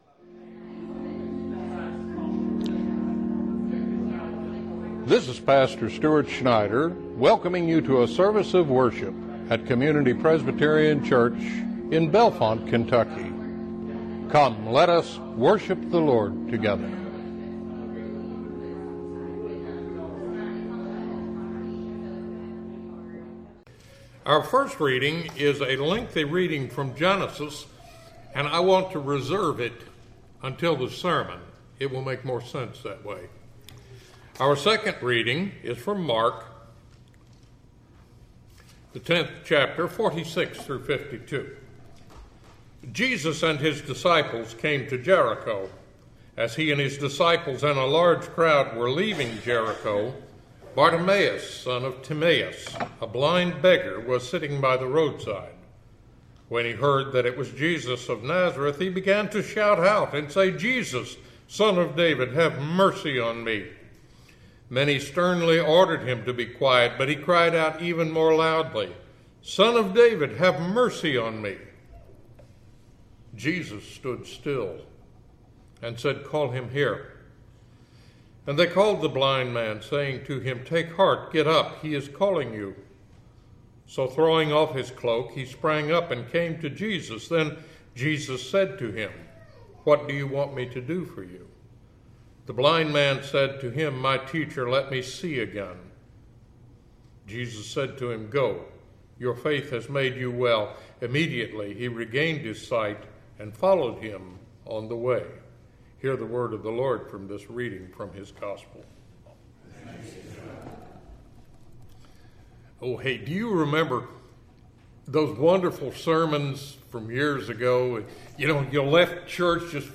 Sermons from Community Presbyterian Church of Bellefonte, Kentucky